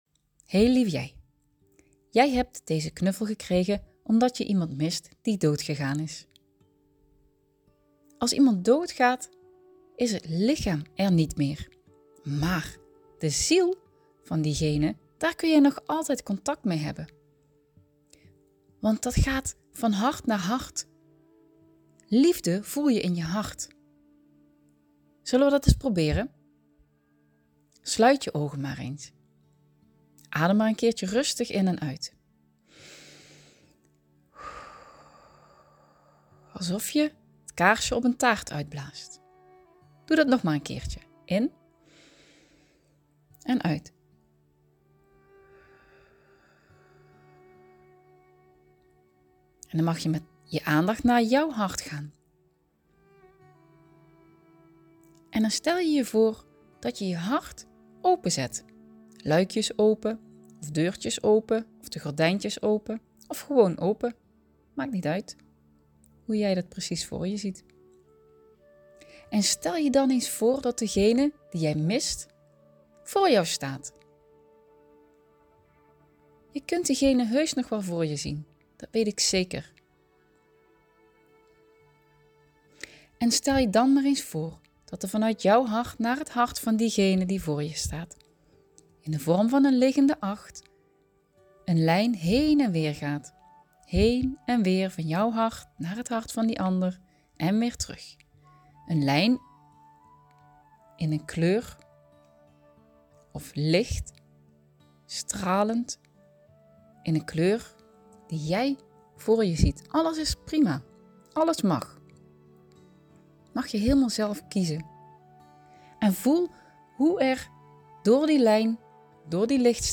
Tijd voor een verhaaltje - Kids meditatie
Kids Meditatie Rouw Mp 3